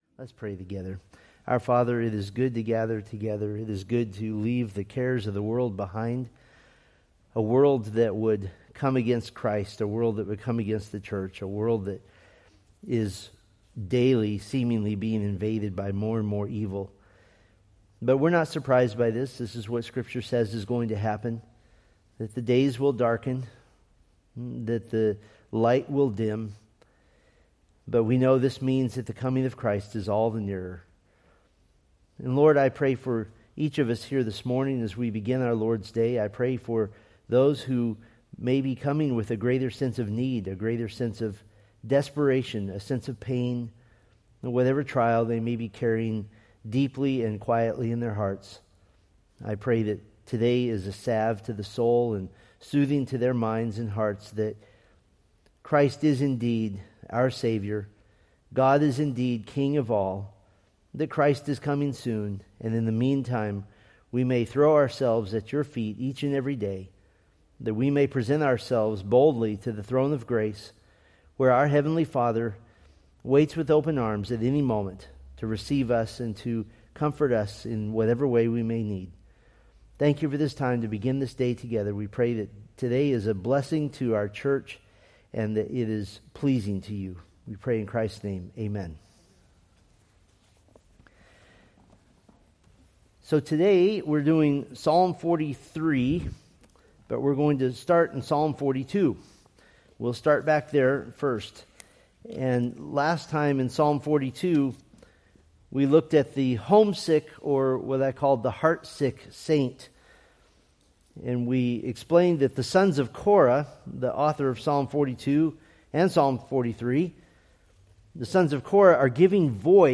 Date: Nov 16, 2025 Series: Psalms Grouping: Sunday School (Adult) More: Download MP3